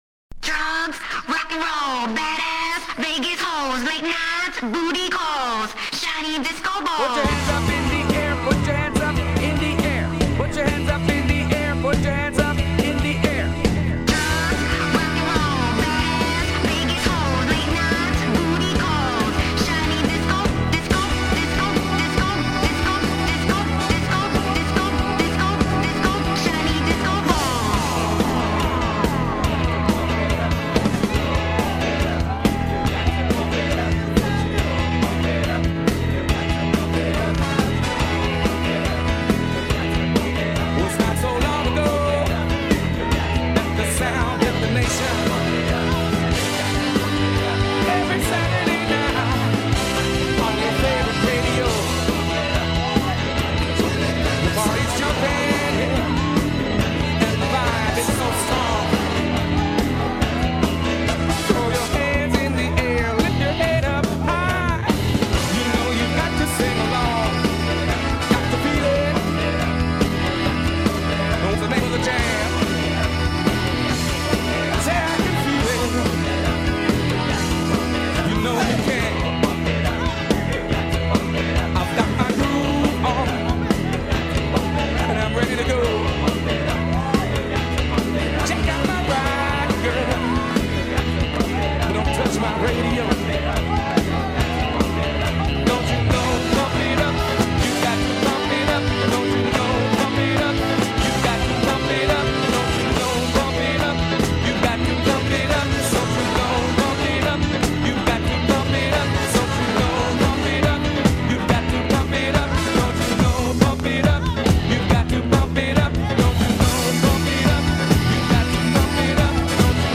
Bootlegs (page 3):